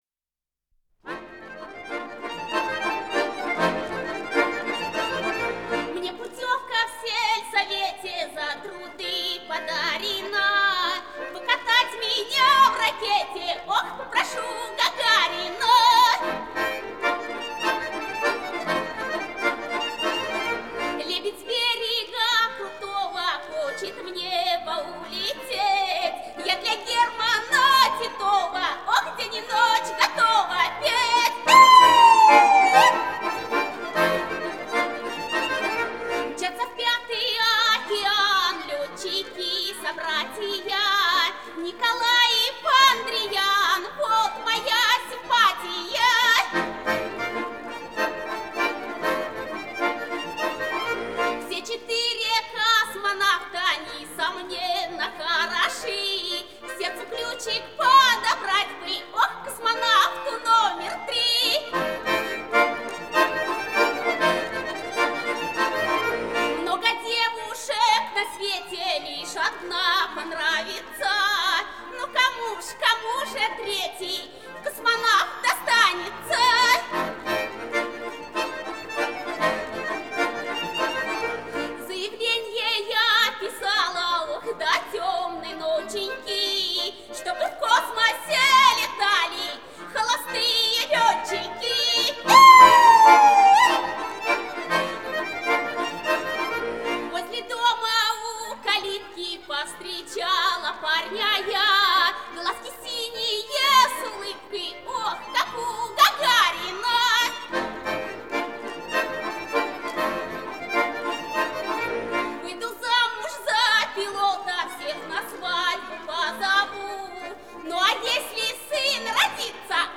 баяны